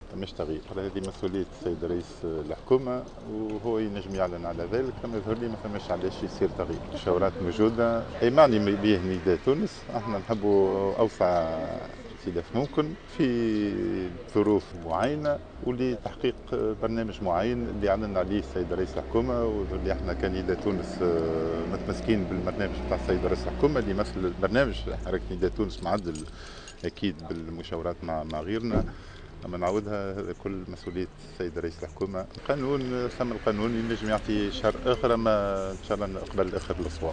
Désigne à la tête du ministère de la santé dans le gouvernement d’Habib Essid, le député et dirigeant de Nidaa Tounes, Said Aïdi a affirmé dans une brève déclaration à Jawhara Fm, qu’il va conserver le portefeuille ministériel qui lui a été attribué.